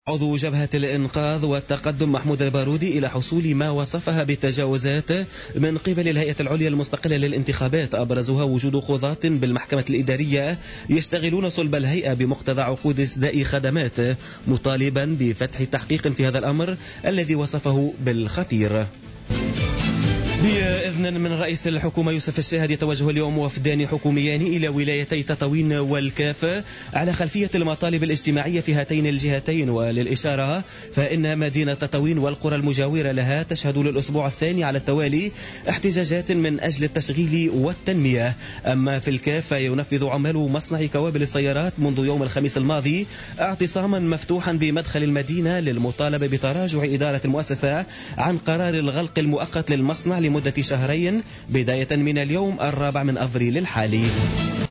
نشرة أخبار منتصف الليل ليوم الثلاثاء 4 أفريل 2017